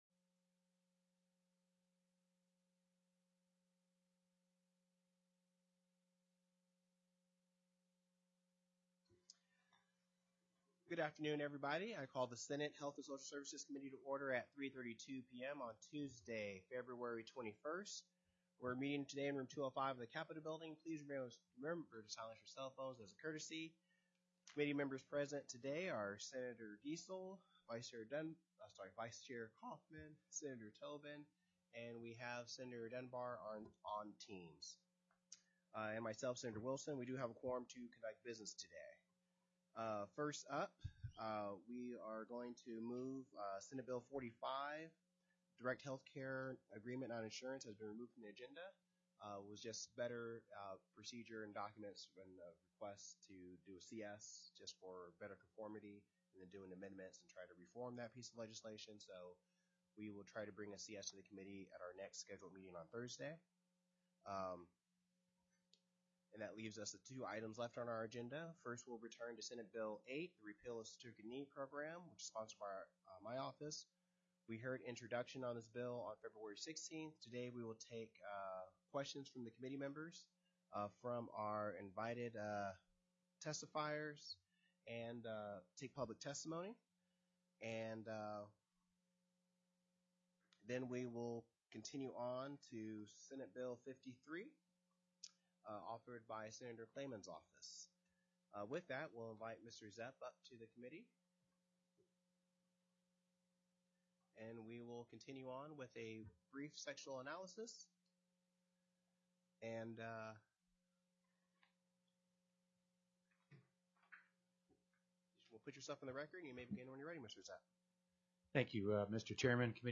The audio recordings are captured by our records offices as the official record of the meeting and will have more accurate timestamps.
Heard & Held -- Invited & Public Testimony --